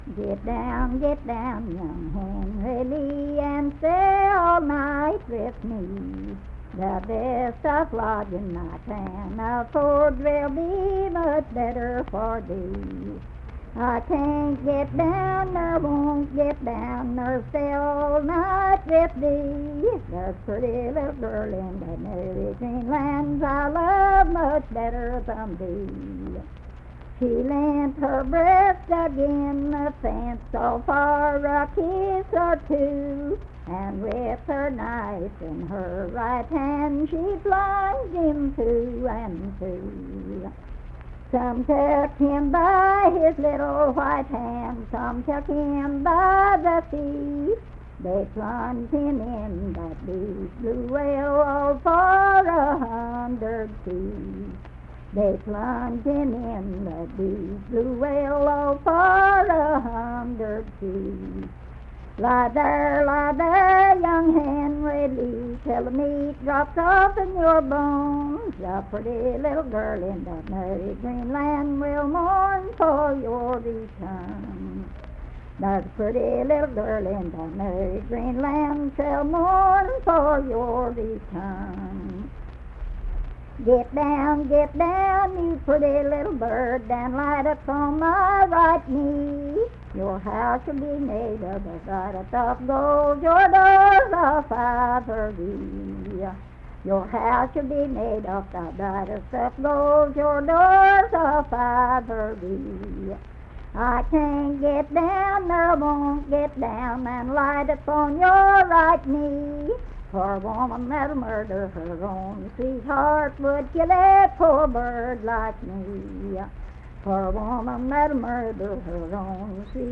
Unaccompanied vocal music
Verse-refrain, 5(8).
Voice (sung)